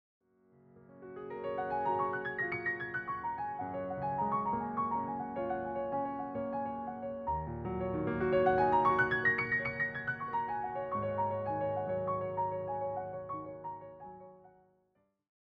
all performed as solo piano arrangements.